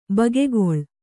♪ bagegol